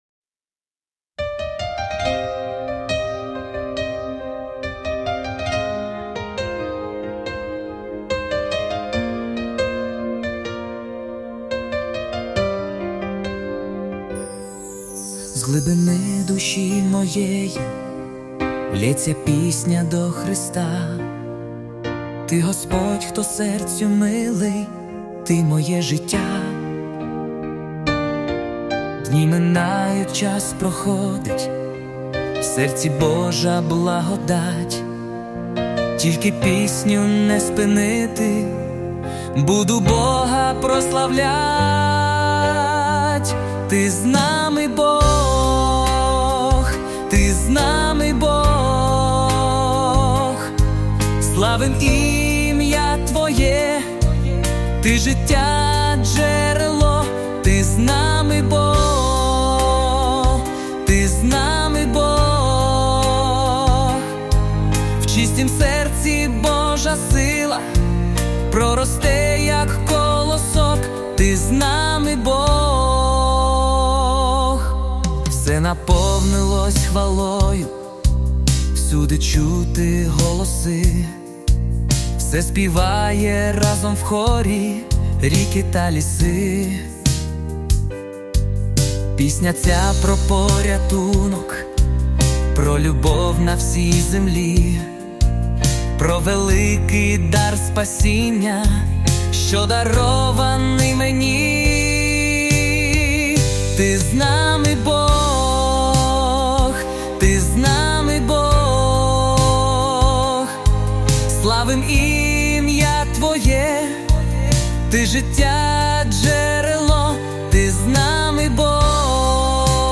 песня ai
Jesus Worship